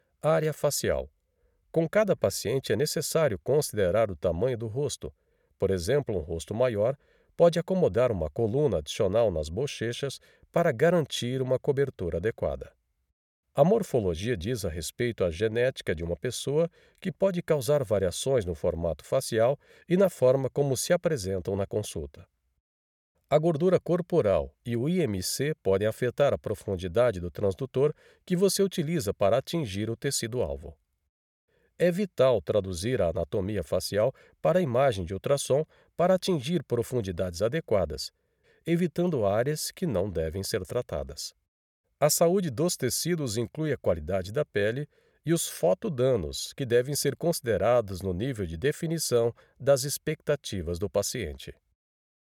Natural, Amable, Empresarial, Comercial, Versátil
Audioguía
He presents his voice with great agility.